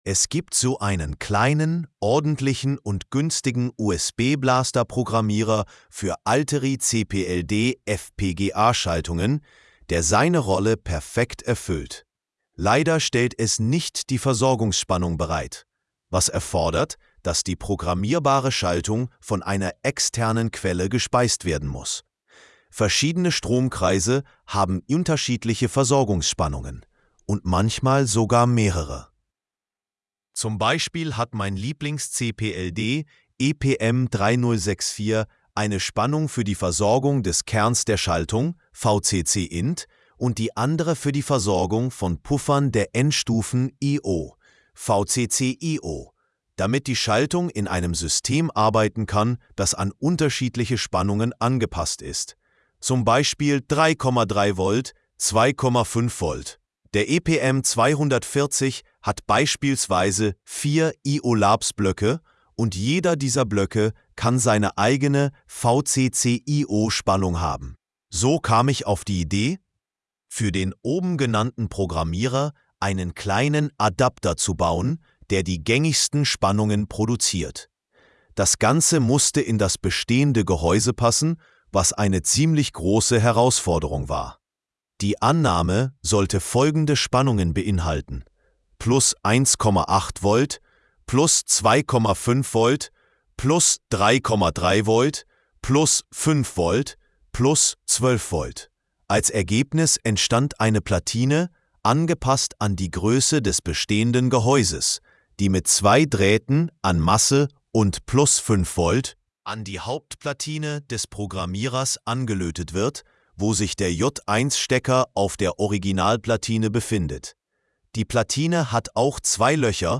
📢 Anhören (AI):